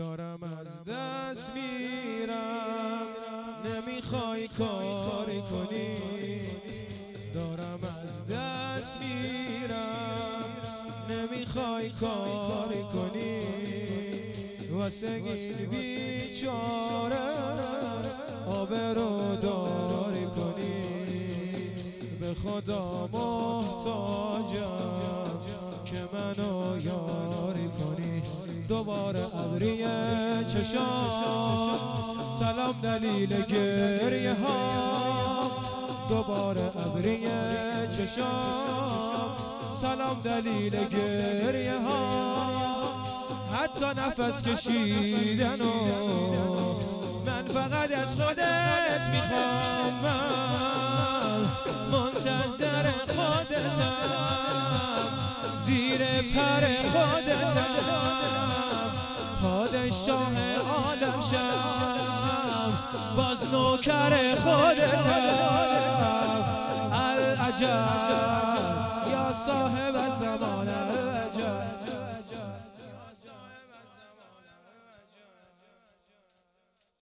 شهادت حضرت زهرا ۷۵ روز ۱۳۹۸